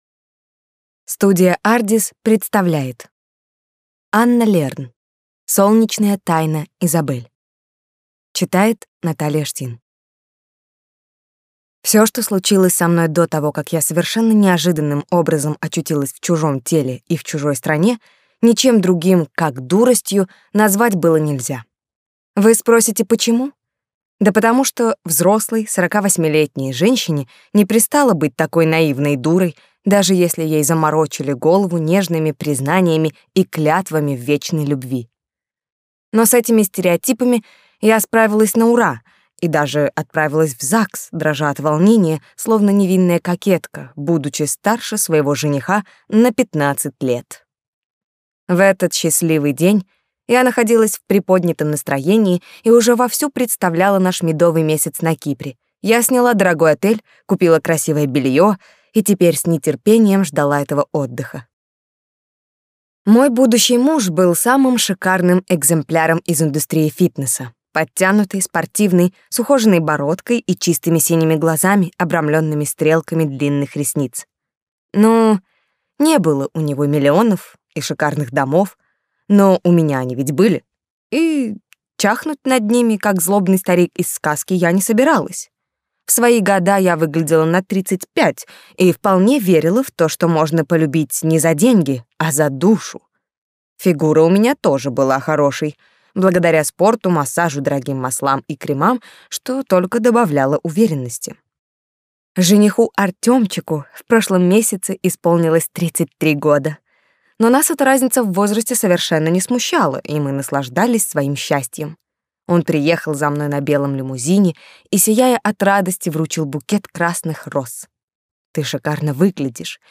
Аудиокнига Солнечная тайна Изабель | Библиотека аудиокниг